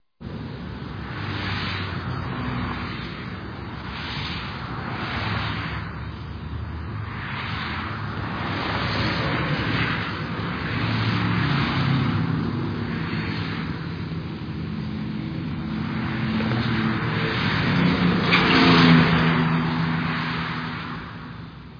moderne-tijd_Verkeer_audiomicro.mp3